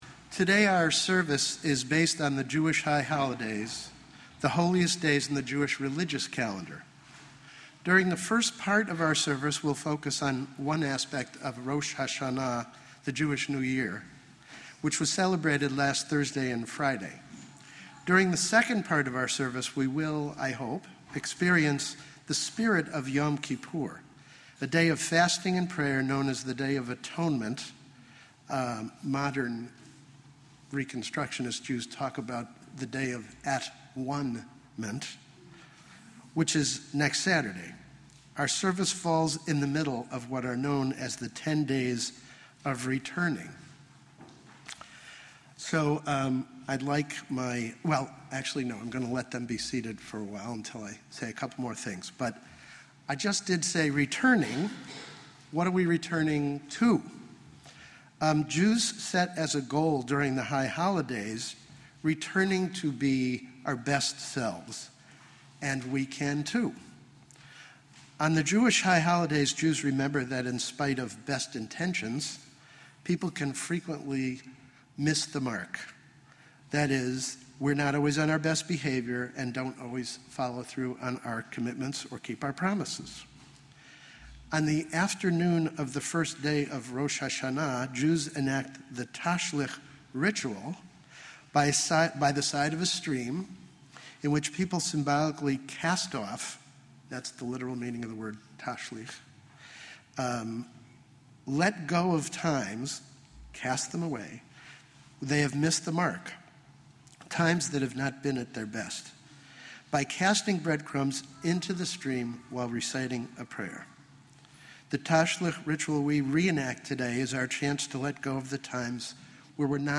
Our children assist in the reenactment in the first part of the service.